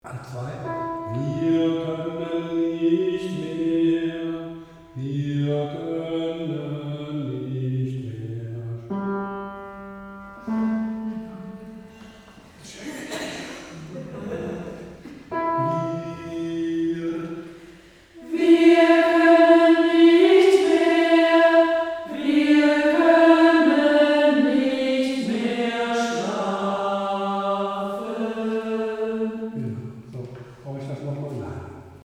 Takt 35 - 42 | Einzelstimmen
Gott in uns! | T 35 | Alt 2